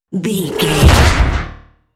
Dramatic creature hit trailer
Sound Effects
Atonal
heavy
intense
dark
aggressive
hits